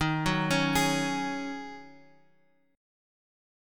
Ebm11 Chord
Listen to Ebm11 strummed